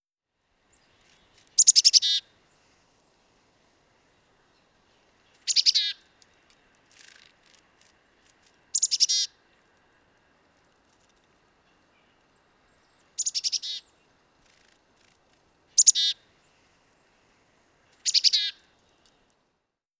Bird5.wav